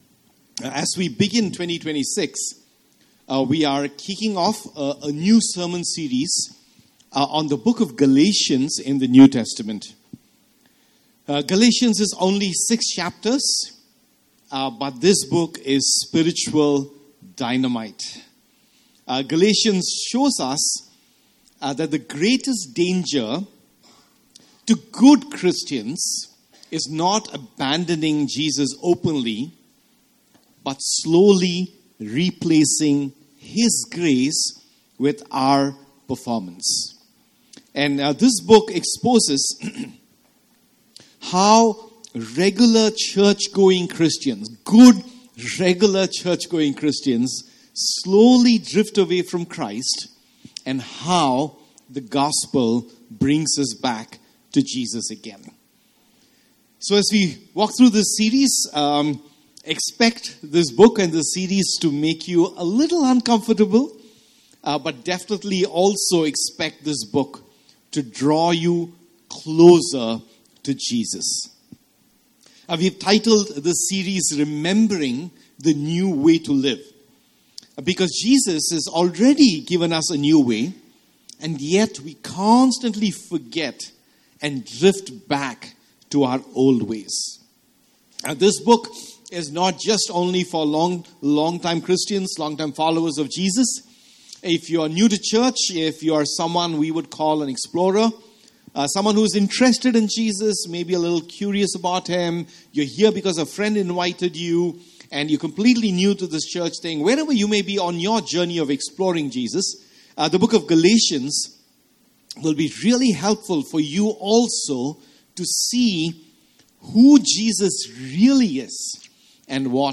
Sermons // Gospel-Centered // Life-Changing // Motivating //